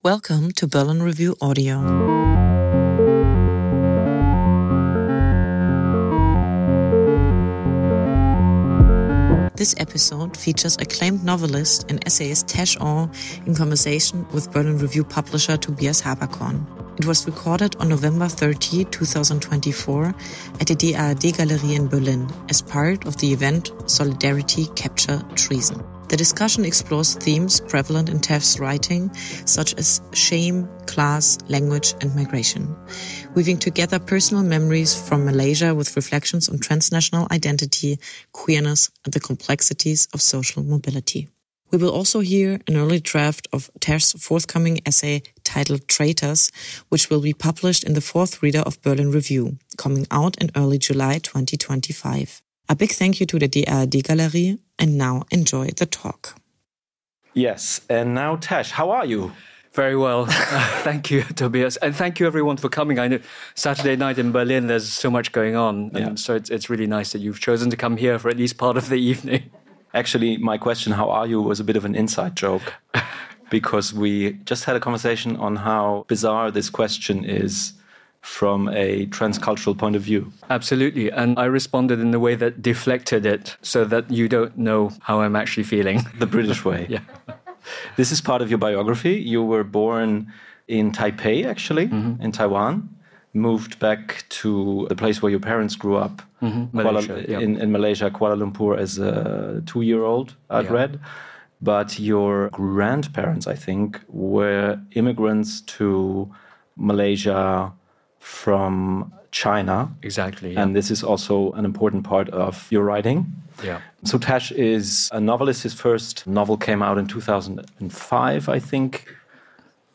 It was recorded in front of a live audience at daadgalerie, Kreuzberg, on November 30, 2024.